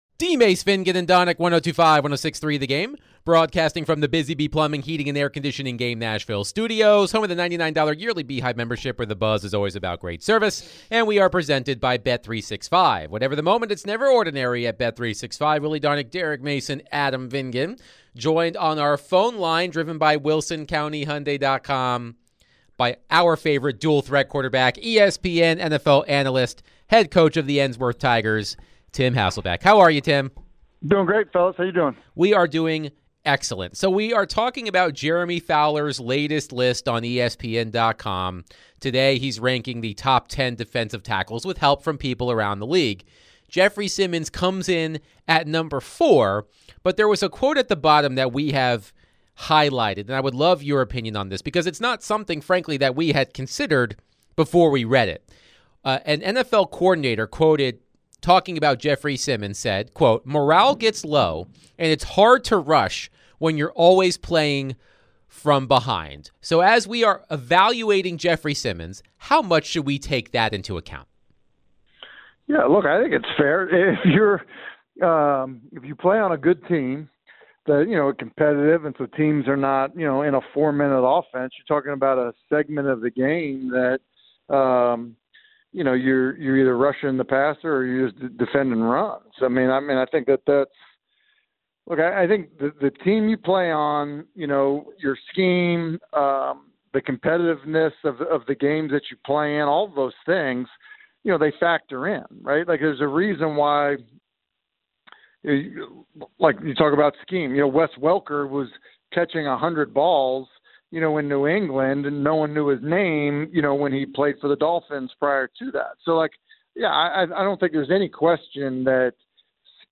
In the second hour of DVD, ESPN NFL Analyst Tim Hasselbeck joins DVD to discuss Jeffery Simmons, the top 10 list, and the difference in the NFL from the 80s to present. They then discuss which play in Titans history they would change if they were able to, besides Kevin Dyson's 1-yard short of a touchdown in Super Bowl 34. They got Listeners' reactions and more.